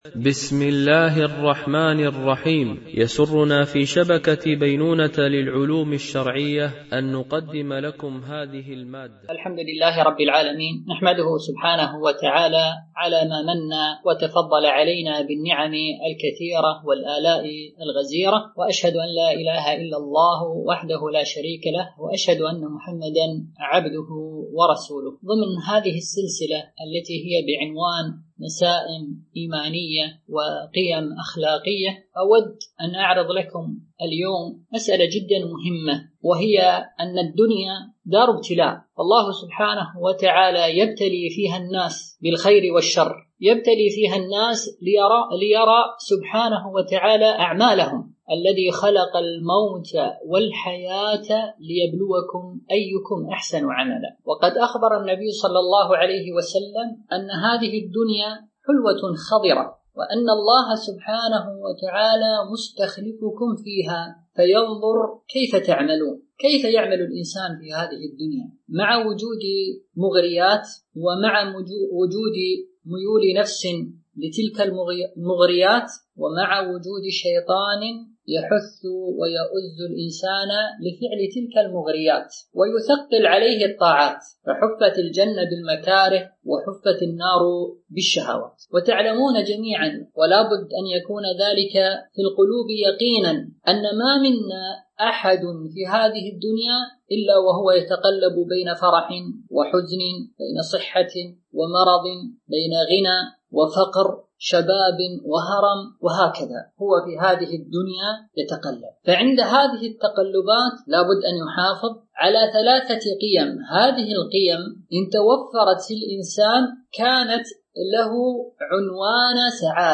سلسلة محاضرات نسائم إيمانية وقيم أخلاقية